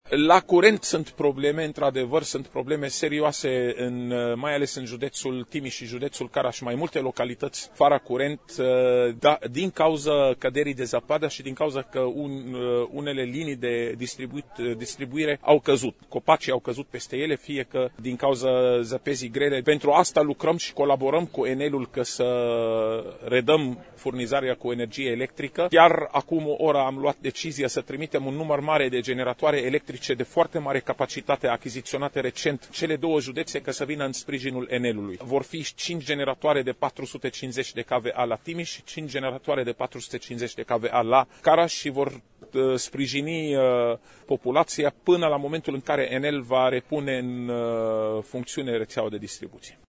In mai multe judete din Romania, mii de oameni au ramas fara electricitate din cauza ninsorilor abundente, dar in aceste judete vor fi trimsie de urgenta generatoare, a declarat astazi pentru Radio Romania Actualitati, secretarul de stat Raed Arafat.